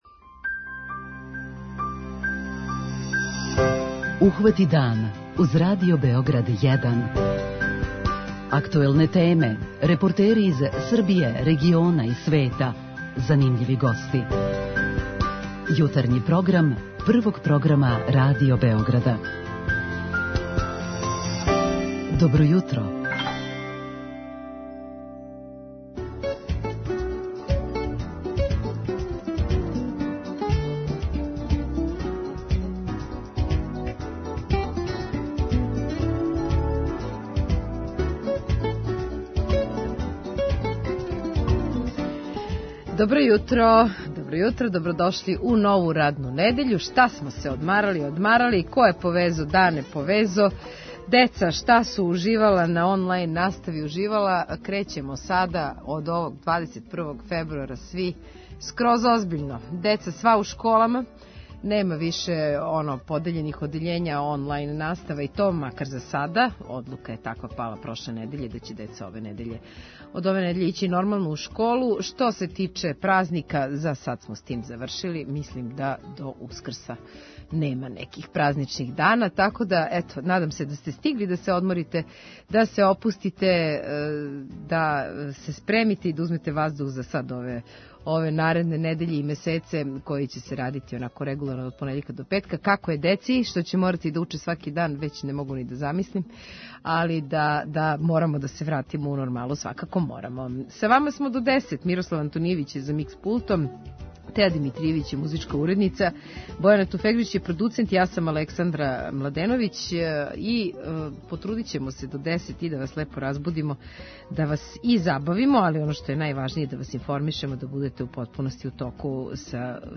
Вас овога јутра питамо како дишете и да ли је свака зима код вас пропраћена оваквим стањем када је ваздух у питању? преузми : 37.78 MB Ухвати дан Autor: Група аутора Јутарњи програм Радио Београда 1!